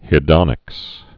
(hĭ-dŏnĭks)